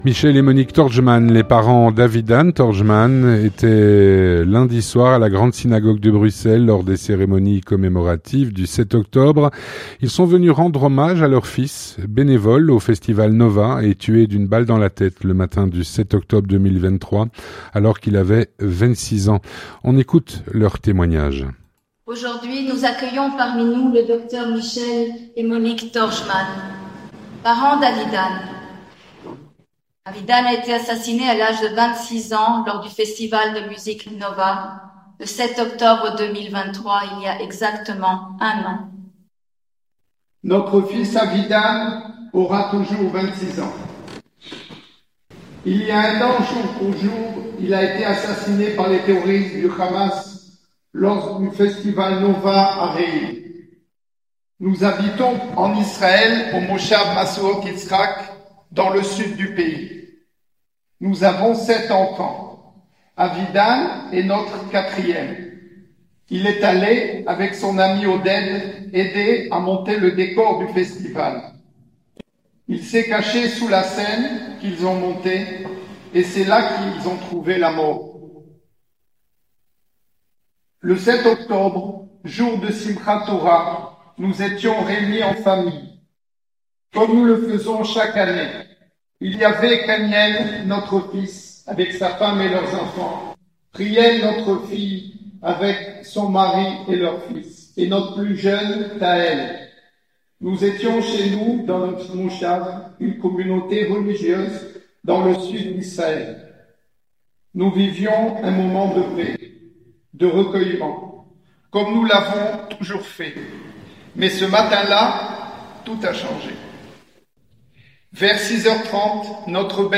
Témoignage
Un reportage